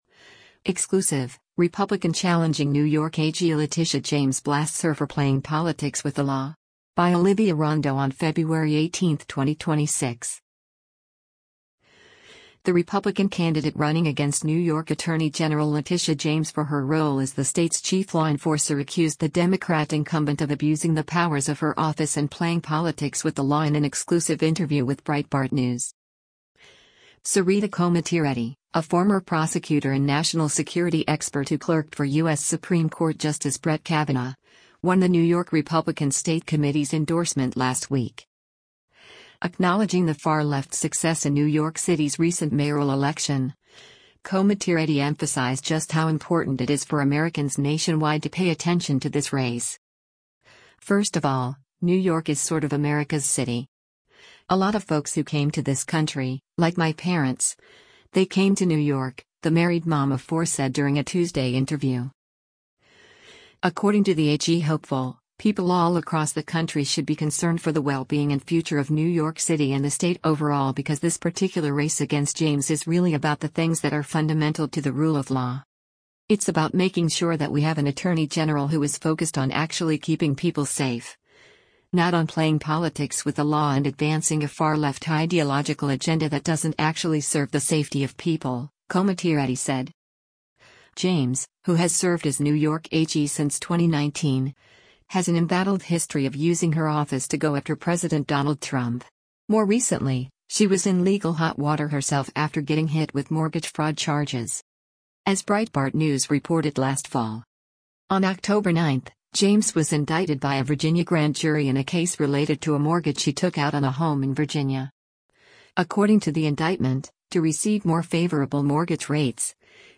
The Republican candidate running against New York Attorney General Letitia James for her role as the state’s chief law enforcer accused the Democrat incumbent of abusing the powers of her office and “playing politics with the law” in an exclusive interview with Breitbart News.